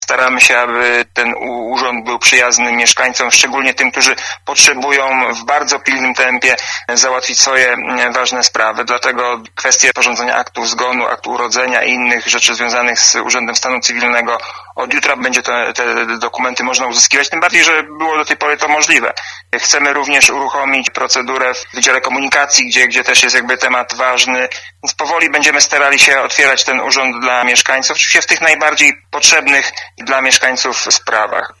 Dariusz Lesicki, wiceprezydent Zielonej Góry